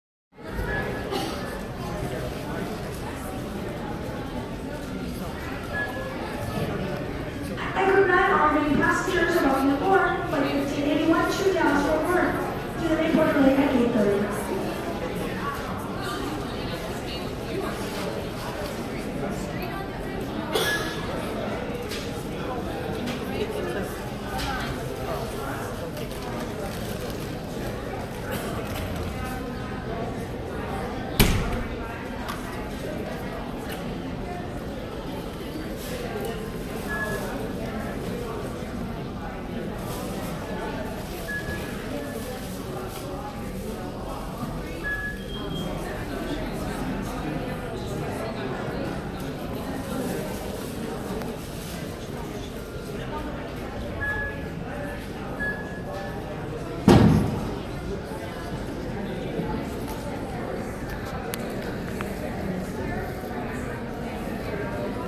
Download Airport sound effect for free.
Airport